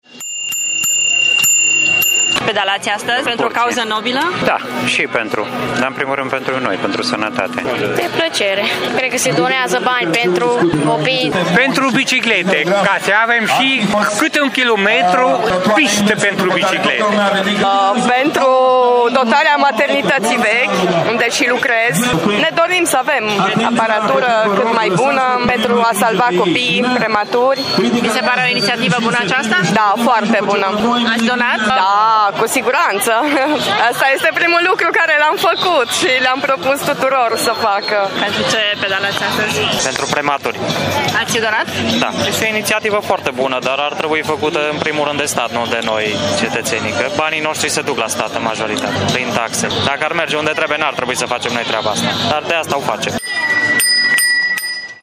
Mulți dintre bicicliștii care au participat la Marș au pedalat și pentru Salvați Copiii.